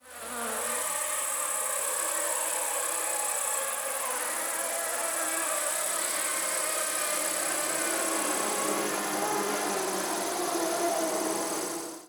Рой опасных пчел
Тут вы можете прослушать онлайн и скачать бесплатно аудио запись из категории «Насекомые, земноводные».